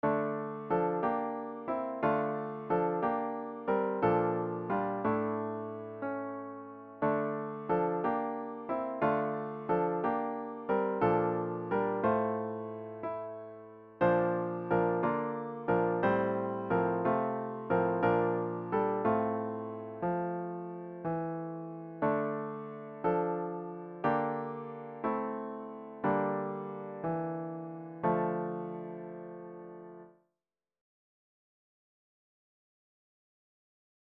The hymn should be performed at a fervent♩. = ca. 60.